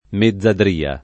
mezzadria
mezzadria [ me zz adr & a ] s. f.